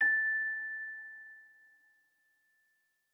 celesta1_9.ogg